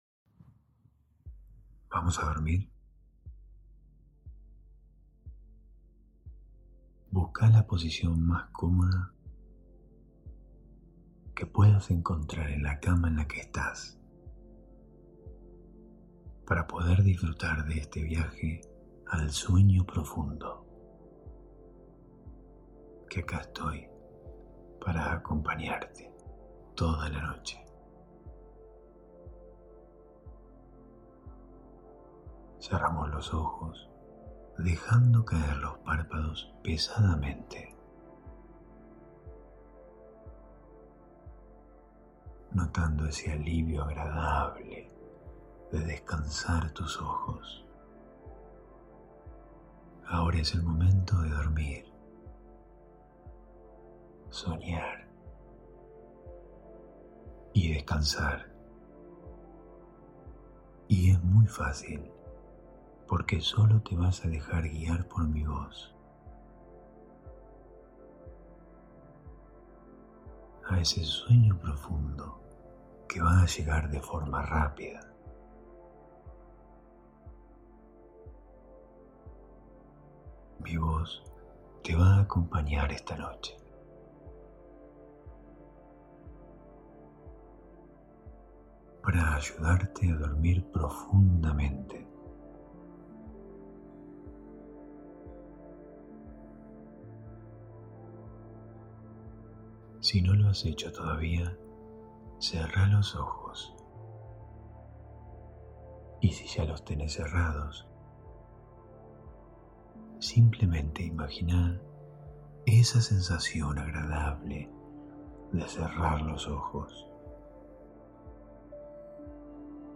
Hipnosis guiada para dormir rápido y sin preocupaciones.